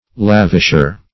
Lavisher \Lav"ish*er\ (-[~e]r), n.